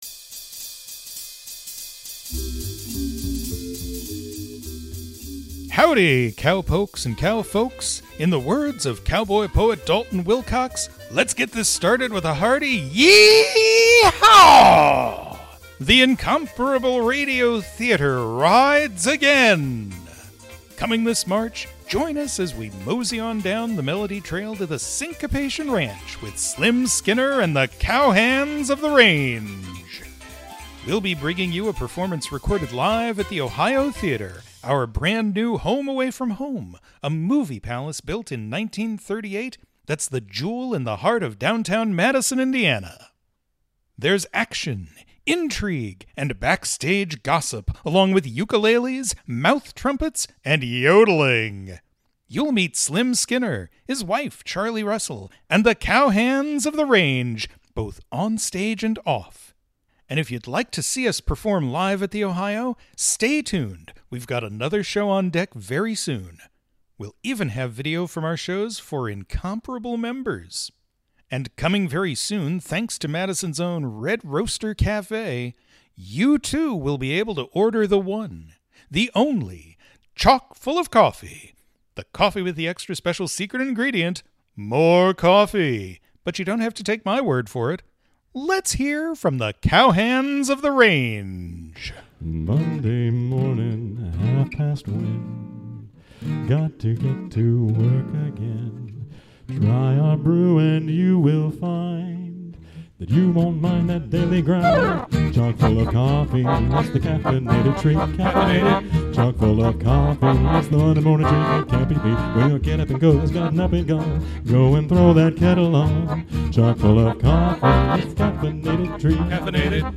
Live from the Ohio Theatre
A preview of the recording from our recent live show, recorded at the Ohio Theatre in Madison, Indiana, our new home away from home for live performances.